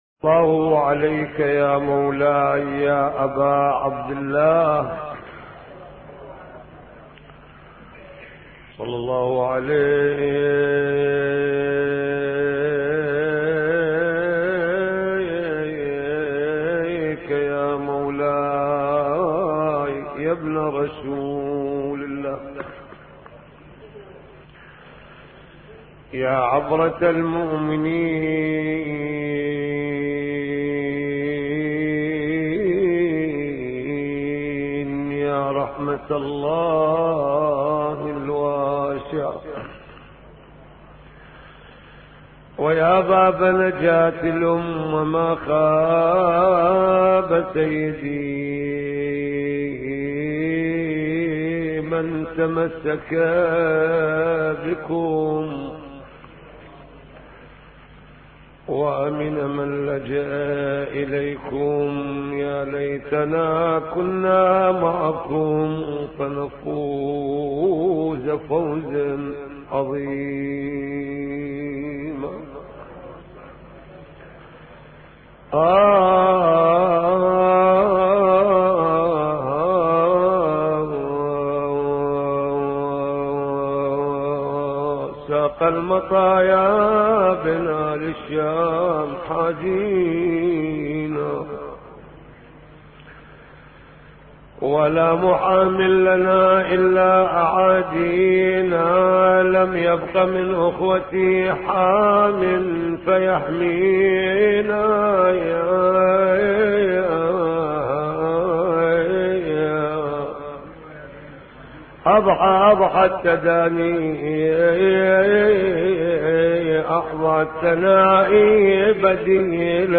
الموعظة